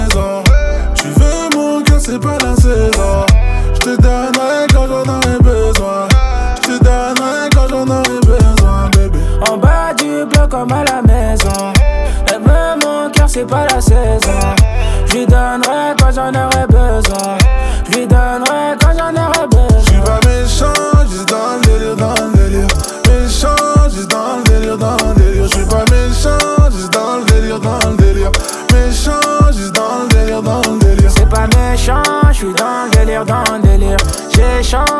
Жанр: Рэп и хип-хоп / Иностранный рэп и хип-хоп
# Hip-Hop